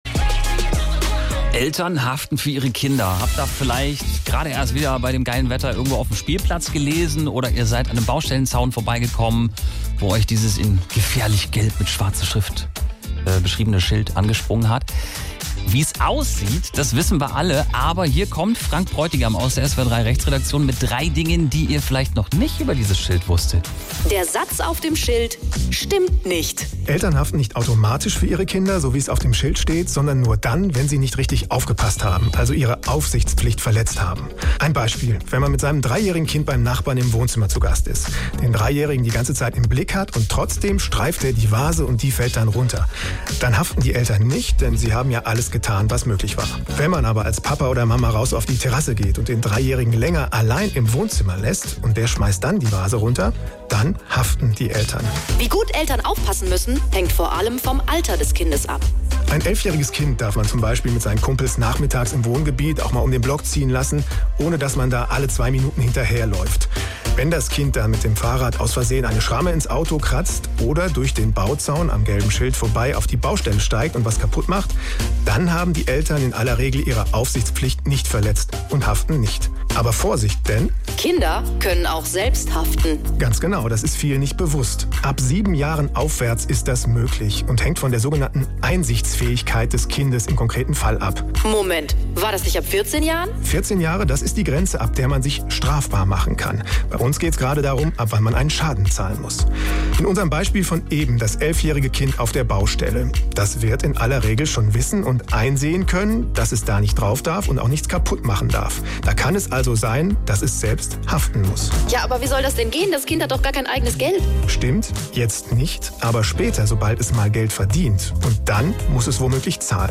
Keine Zeit zu lesen?